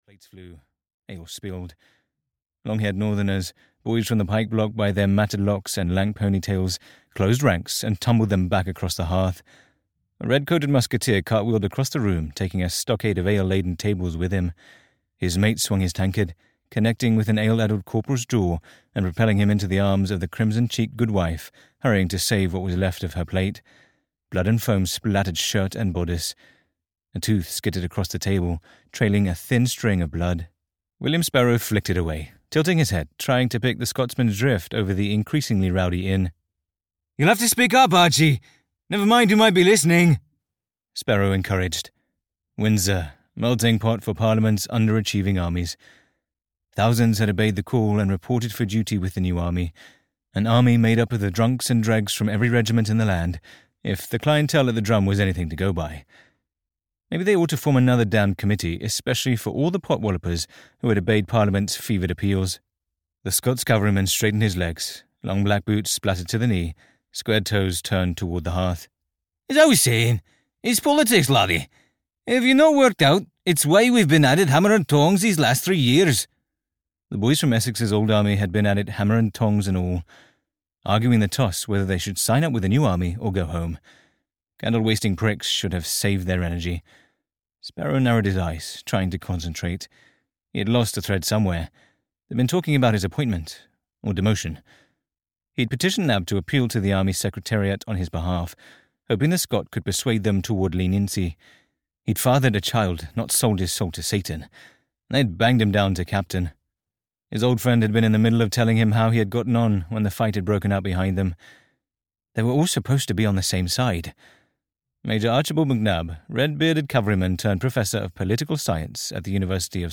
Black Tom's Red Army (EN) audiokniha
Ukázka z knihy